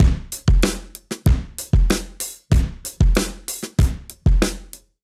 Index of /musicradar/sampled-funk-soul-samples/95bpm/Beats
SSF_DrumsProc1_95-02.wav